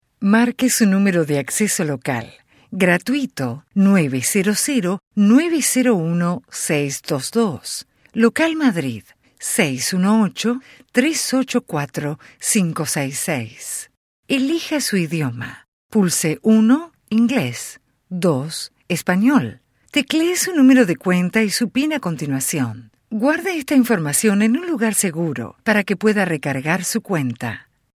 Muestras de voz nativa
IVR
Micrófono Neumann, Interfaz Motu, MacBook Air, Estudio con cabina insonorizada
CreíbleSensualCálidoConversacionalNeutral